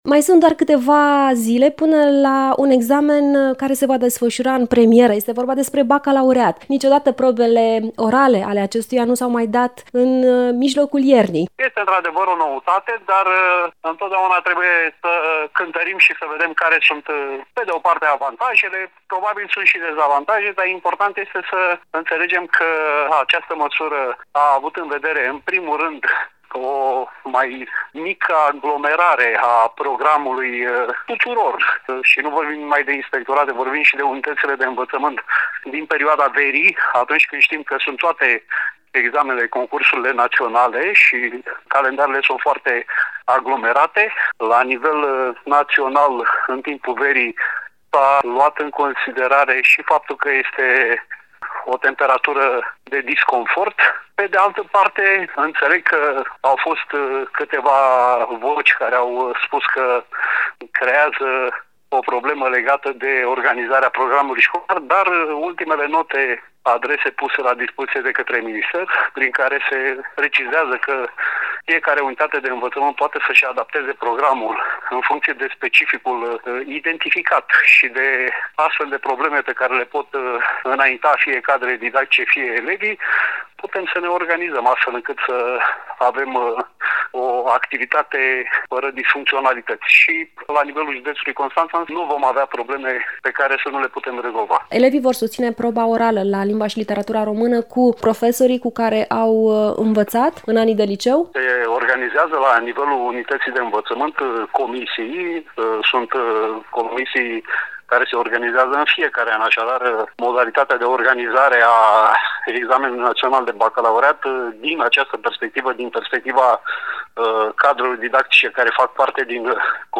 Inspectorul general al Inspectoratului  Școlar Județean Constanța, profesorul Sorin Mihai a declarat pentru postul nostru de radio că schimbarea în calendarul probelor de Bacalaureat vine în avantajul elevilor: